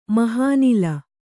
♪ mahānila